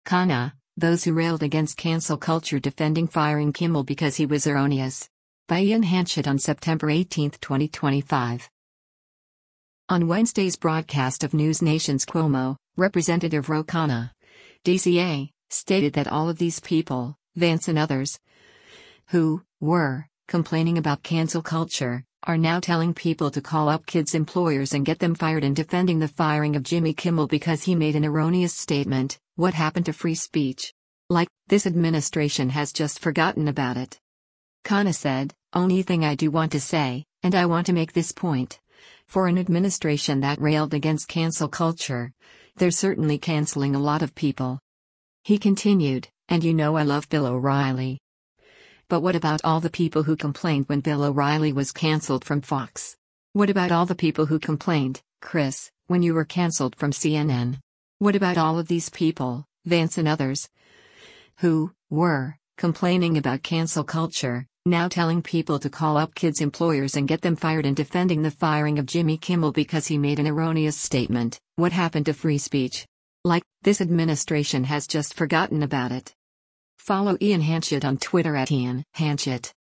On Wednesday’s broadcast of NewsNation’s “Cuomo,” Rep. Ro Khanna (D-CA) stated that “all of these people, Vance and others, who [were] complaining about cancel culture,” are “now telling people to call up kids’ employers and get them fired and defending the firing of Jimmy Kimmel because he made an erroneous statement, what happened to free speech? Like, this administration has just forgotten about it.”